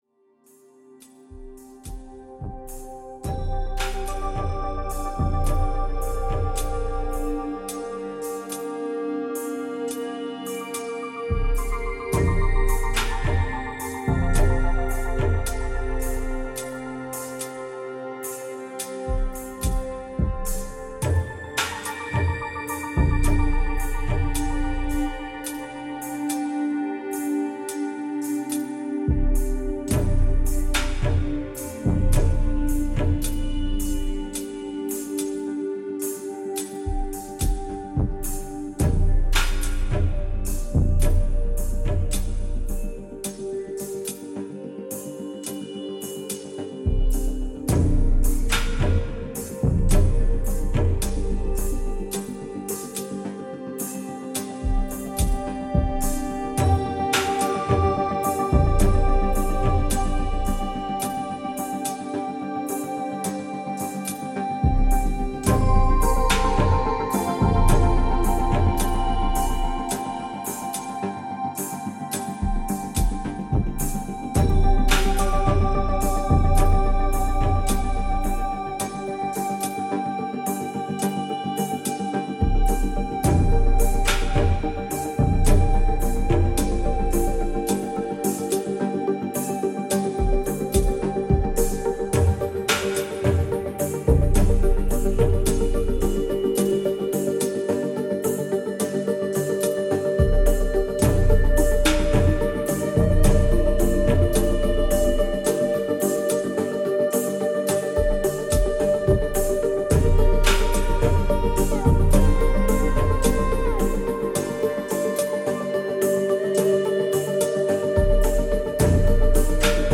ノルウェーのアンビエント系鬼才ギタリスト、2017年最新作！
エレクトロニクス、ギターによるドリーミーなファンタジックな音世界が展開。